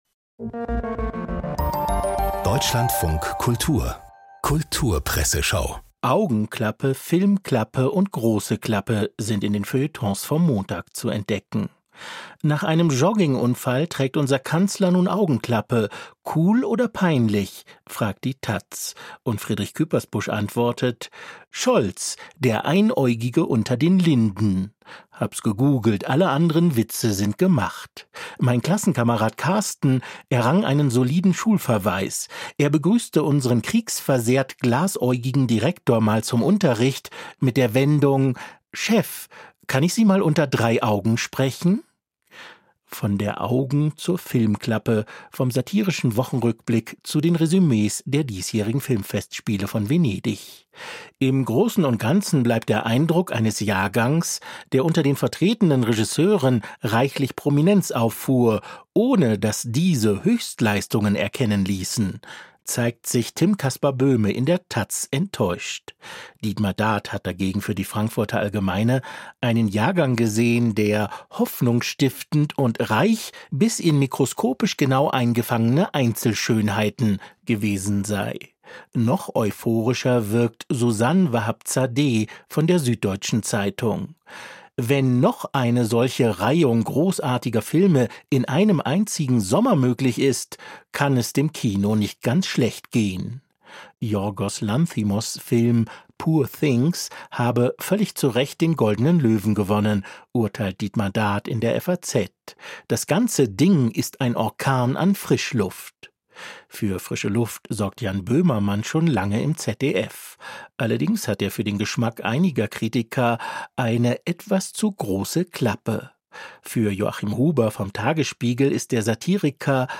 Salman Rushdie und Daniel Kehlmann beim Internationalen Literaturfestival - 10.09.2023